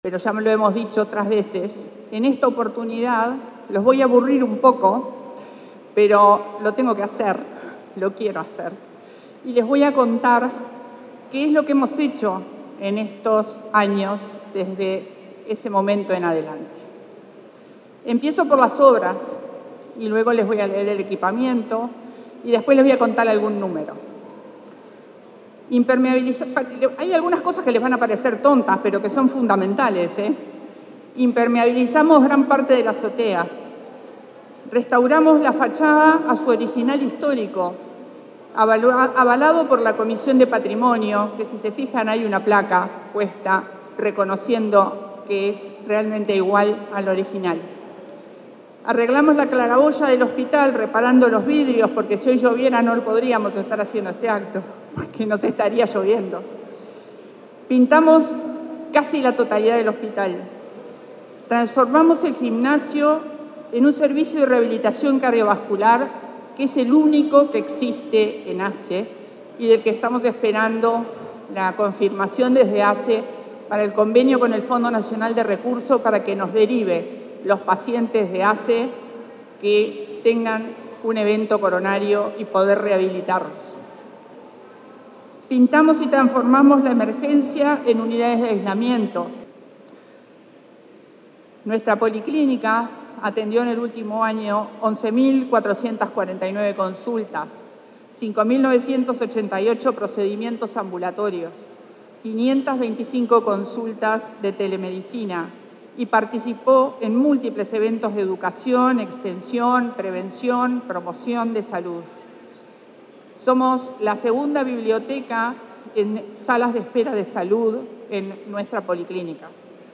Acto por el aniversario del Hospital Español